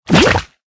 CHQ_FACT_lava_fall_in.ogg